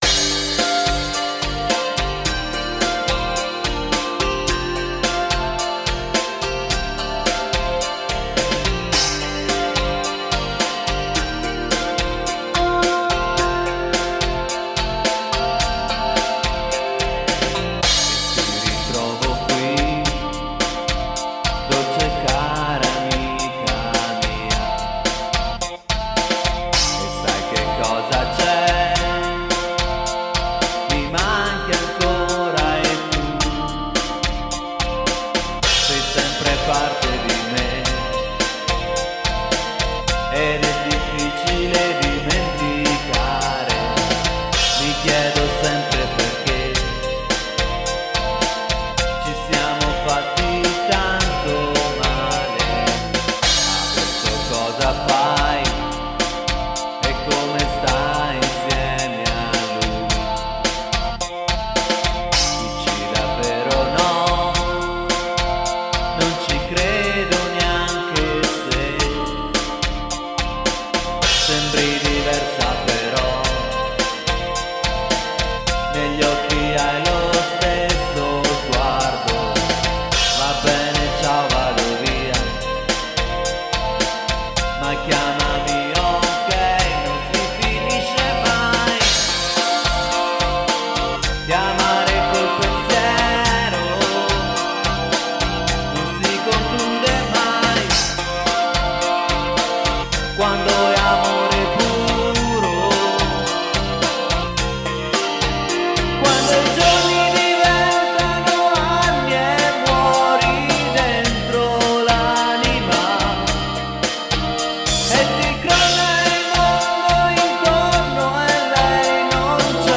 • Multitrack Recorder Zoom MRS-4